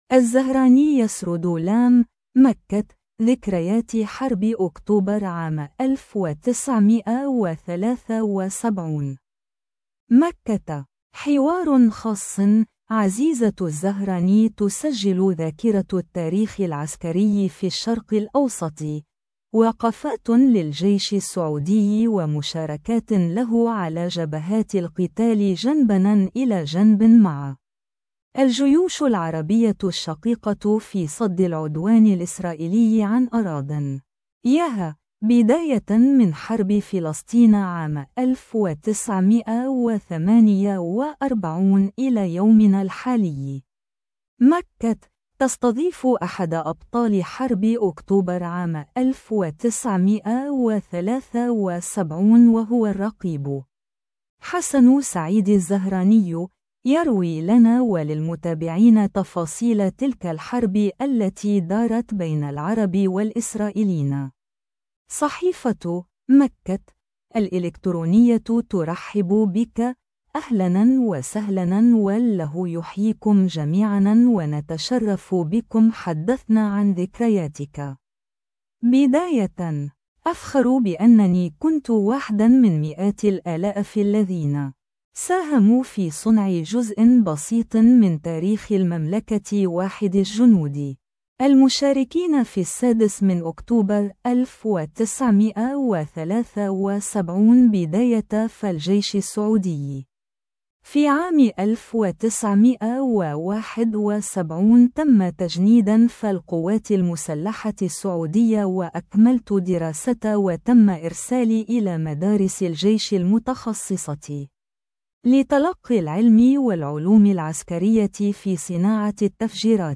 (مكة ) - حوار خاص